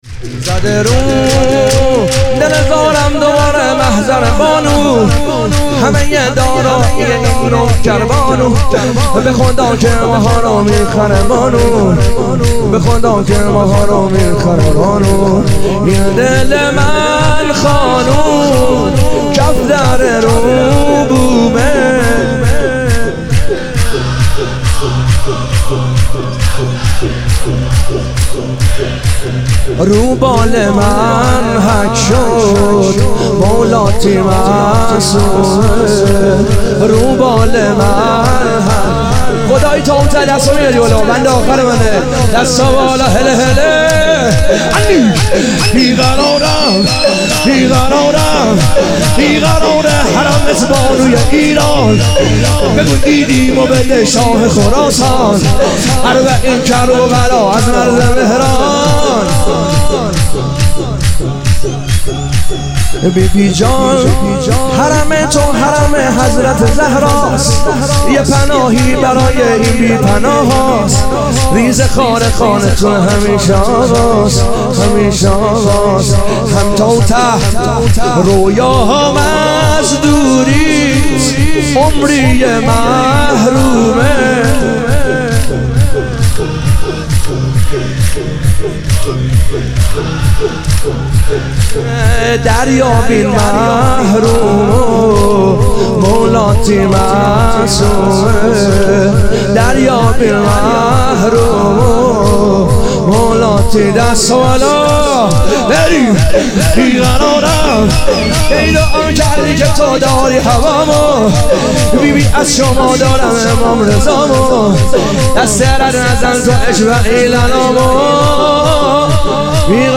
ظهور وجود مقدس امام رضا علیه السلام - شور